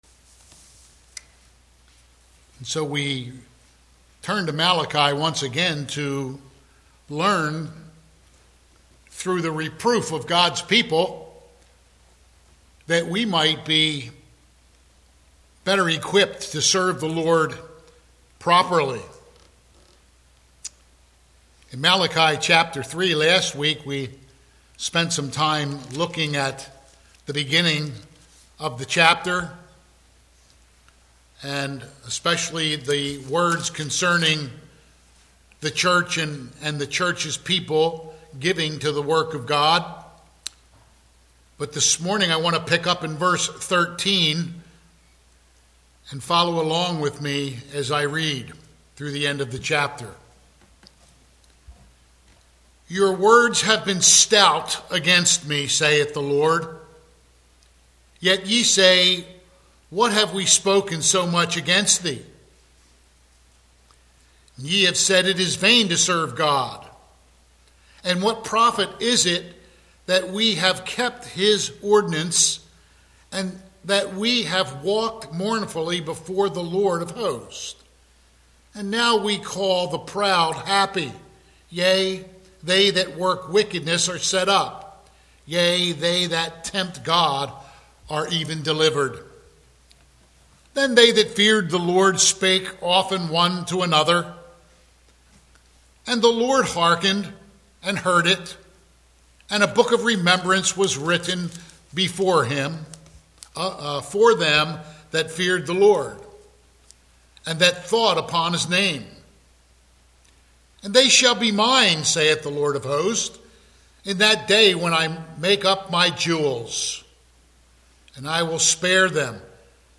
Malachi 3:13-18 Service Type: Sunday AM « January 7